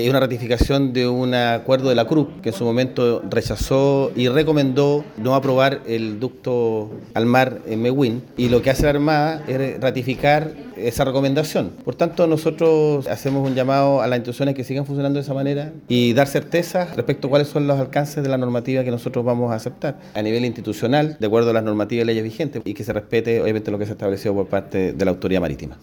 El delegado Presidencial de Los Ríos, Jorge Alvial, entre otras cosas, llamó a respetar lo establecido por la Autoridad Marítima.